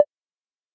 tonal_taps
tap6.ogg